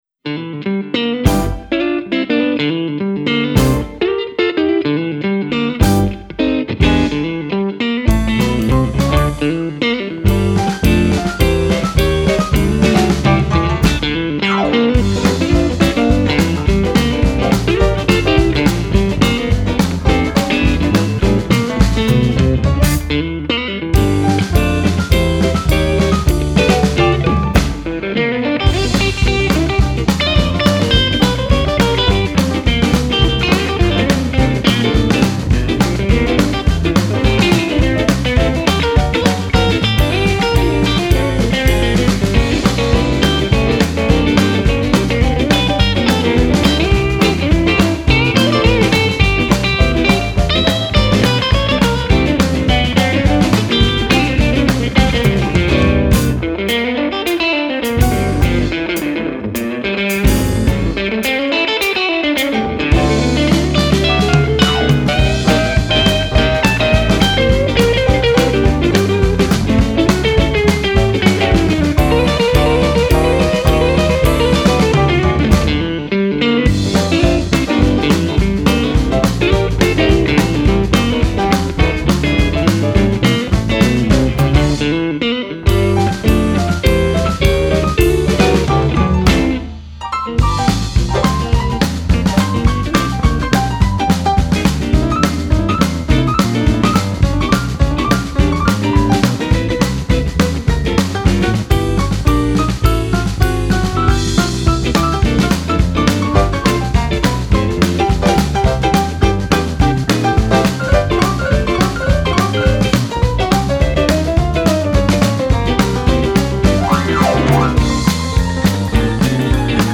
1587   07:20:00   Faixa:     Rock Nacional
Guitarra